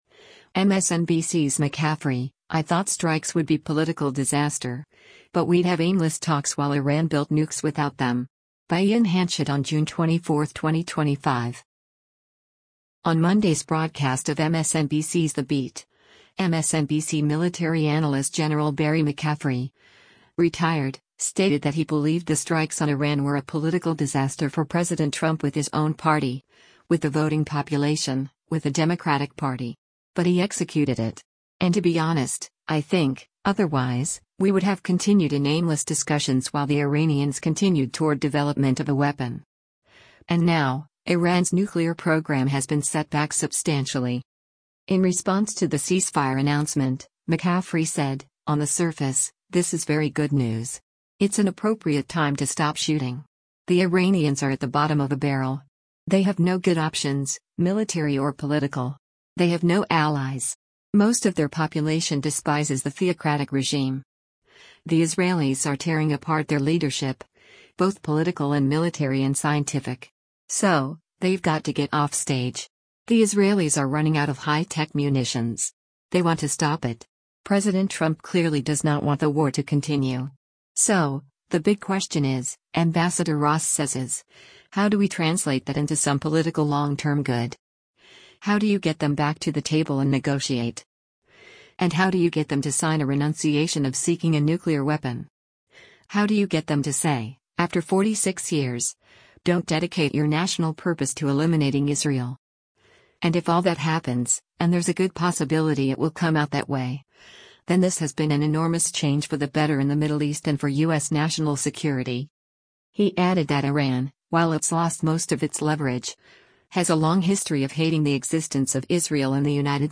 On Monday’s broadcast of MSNBC’s “The Beat,” MSNBC Military Analyst Gen. Barry McCaffrey (Ret.) stated that he believed the strikes on Iran were “a political disaster for President Trump with his own party, with the voting population, with the Democratic Party.